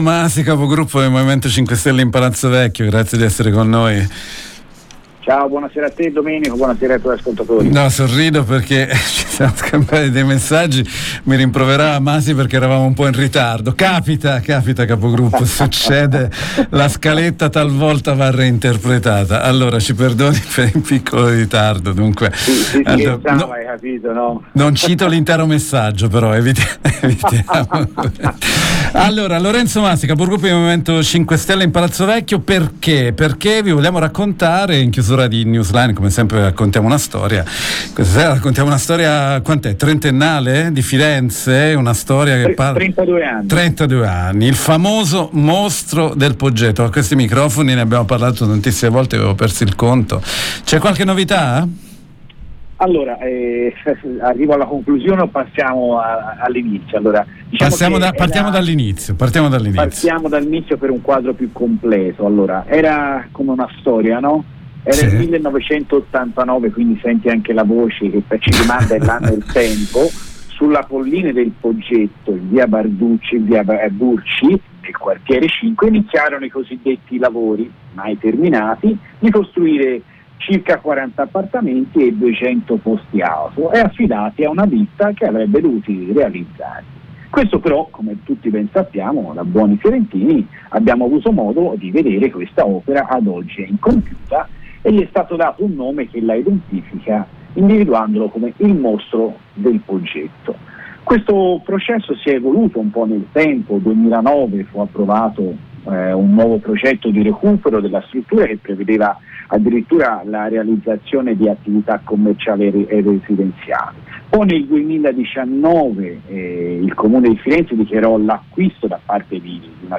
Lo abbiamo intervistato